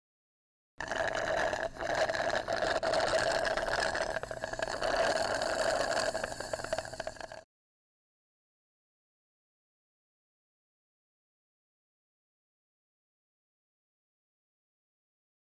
دانلود صدای نوشیدن با نی 1 از ساعد نیوز با لینک مستقیم و کیفیت بالا
جلوه های صوتی